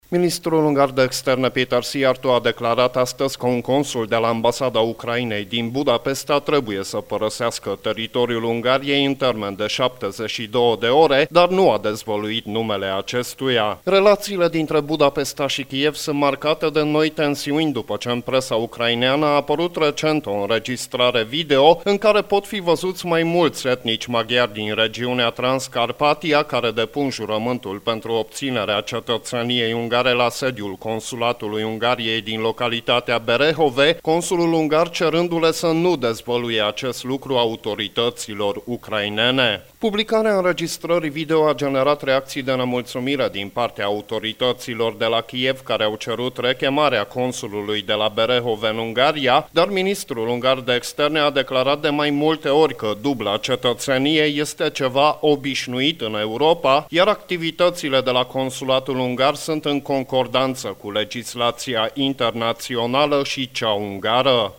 O corespondenţă